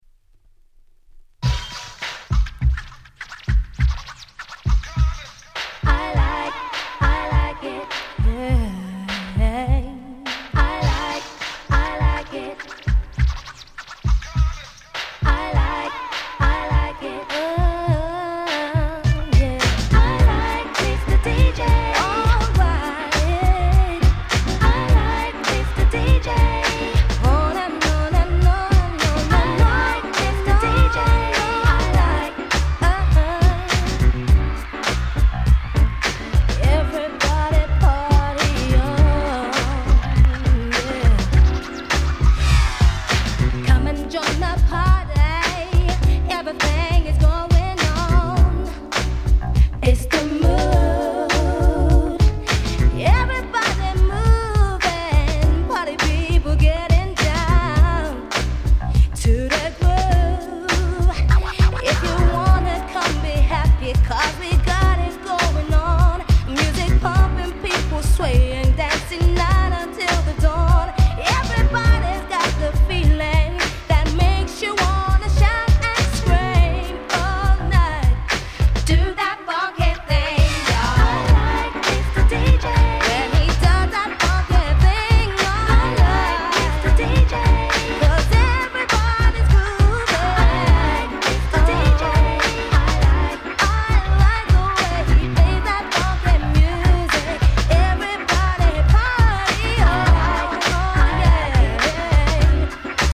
96' Nice UK R&B !!